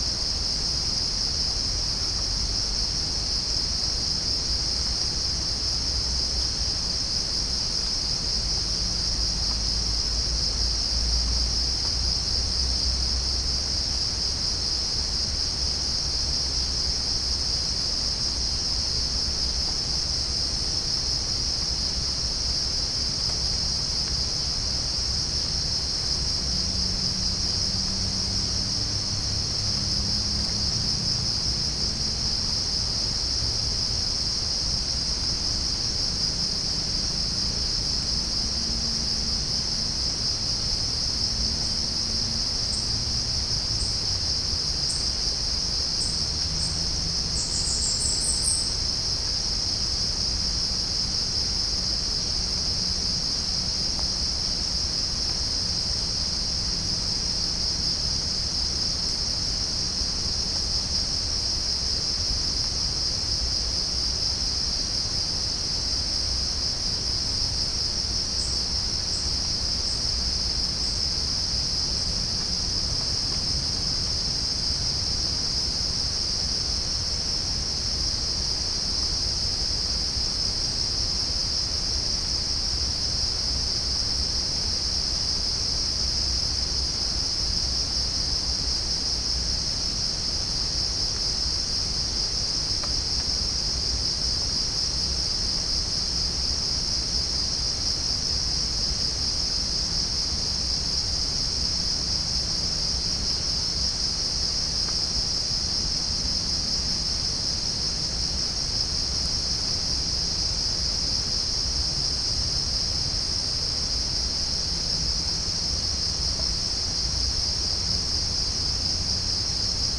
Chalcophaps indica
Pycnonotus goiavier
Pycnonotus aurigaster
Halcyon smyrnensis
Orthotomus ruficeps
Dicaeum trigonostigma